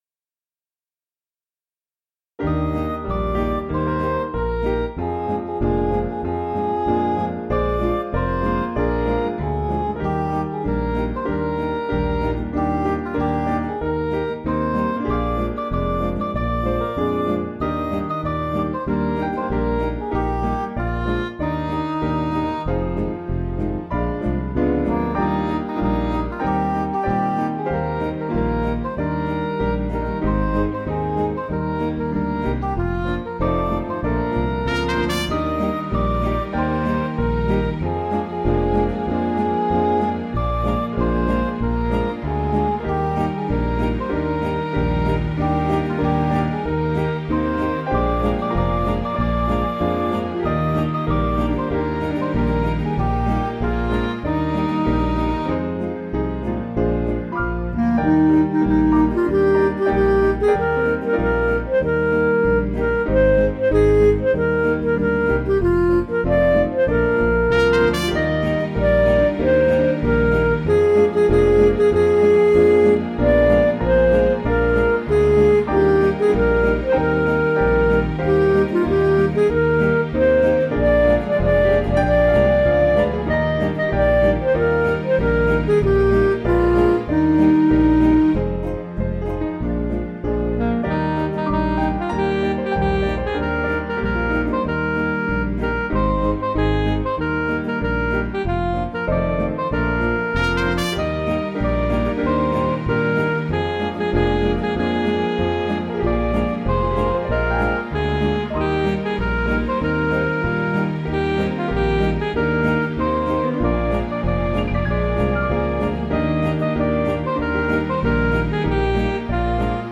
(CM)   5/Eb
Midi